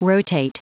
Prononciation du mot rotate en anglais (fichier audio)
Prononciation du mot : rotate